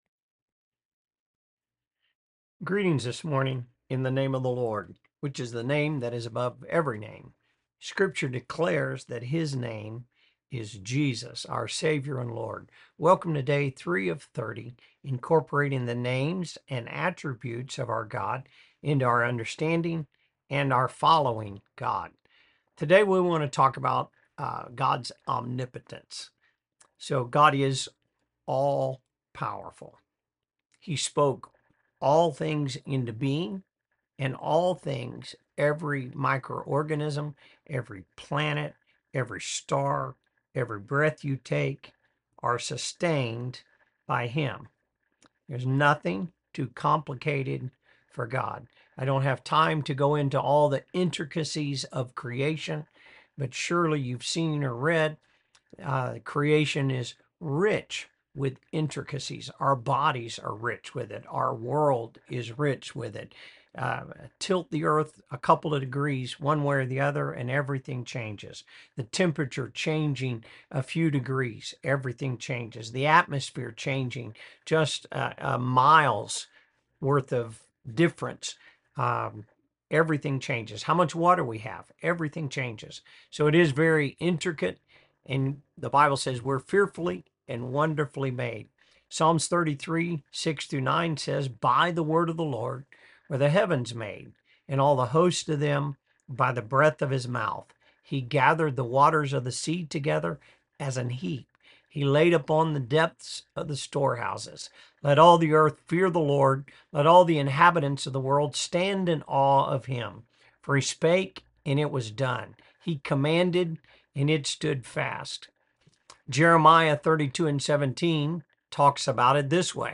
Sermons | Elkhart Life Church